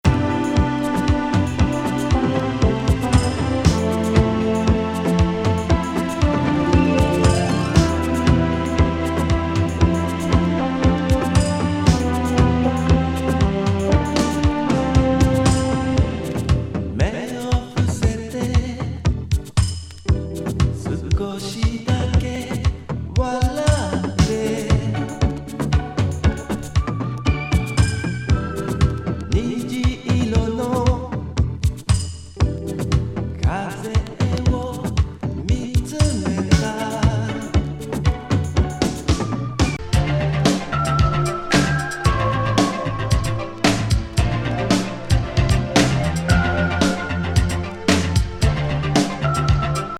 極上エレクトロニクス・グルーブ
エレクトロ・ビート